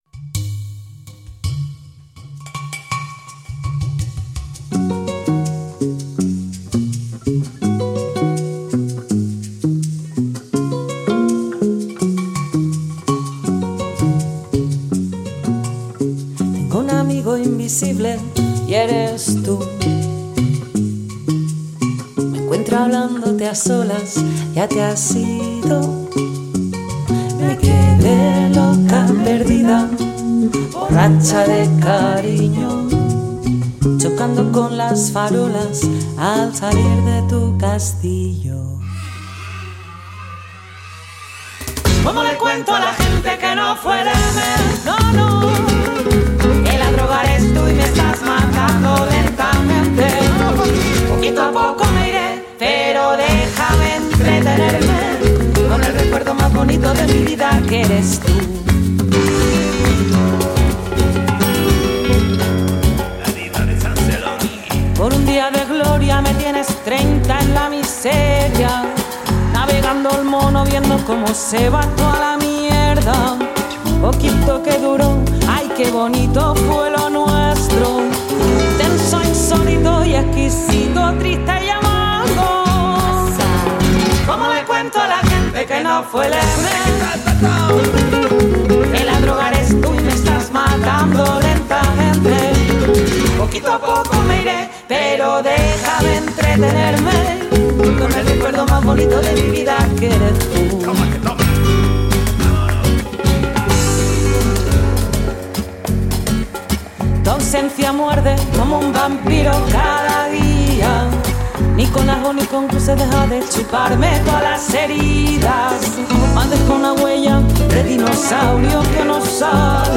Guitarras
Bajo
Percusión
Trompeta